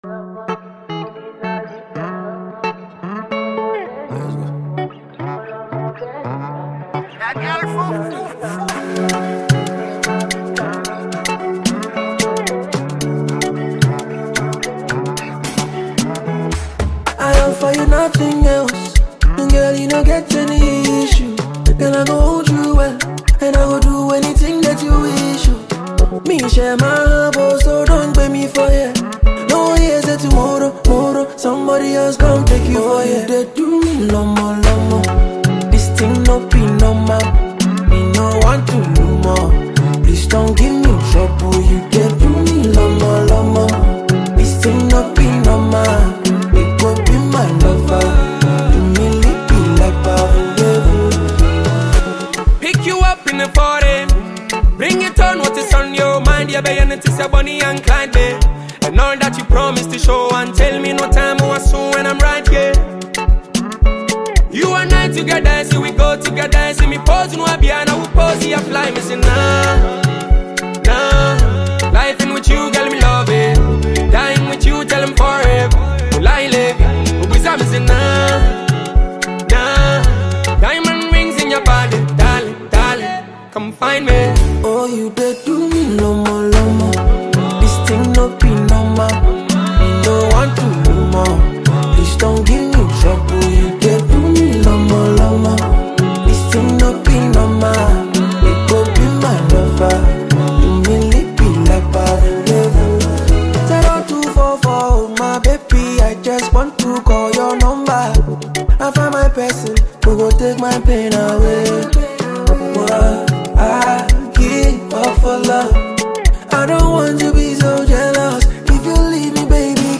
voice, as always, is soothing and mesmerizing